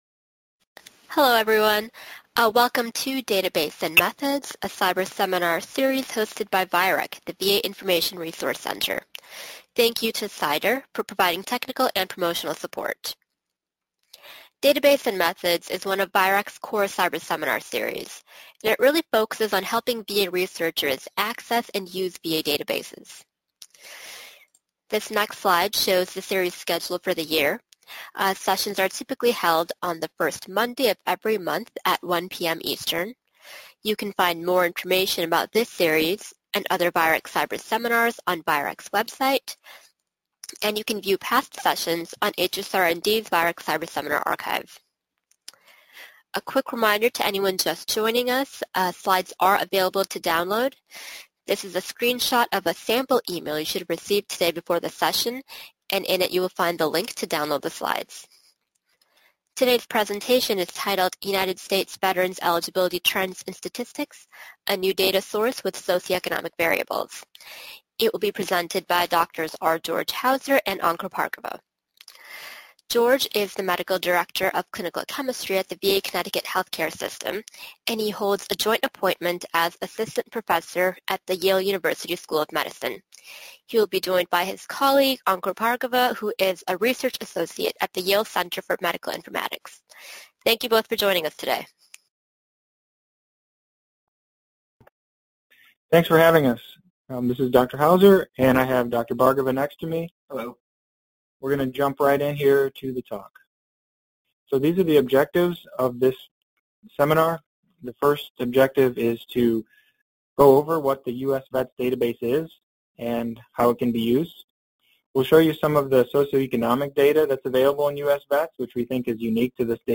VIReC Database and Methods Seminar